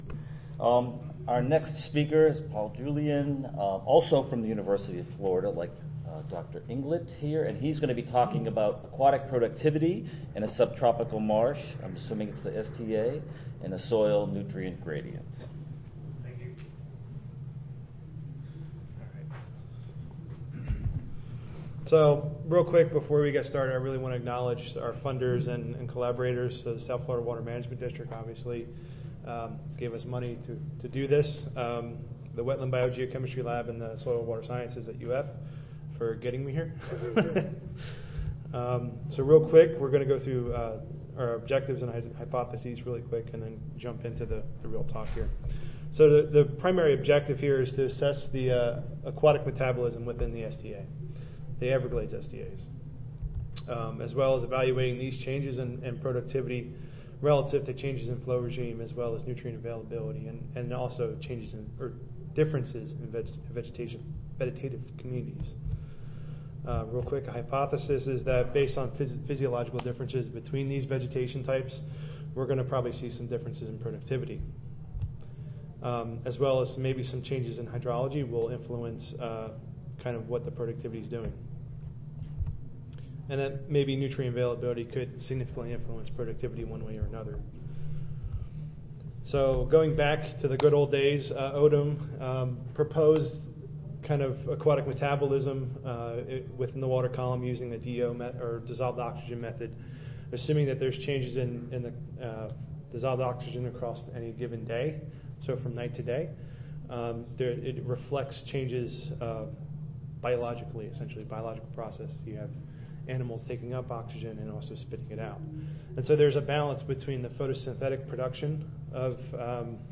Session: Soil Processes and Performance in Constructed Wetlands (ASA, CSSA and SSSA International Annual Meetings)
Recorded Presentation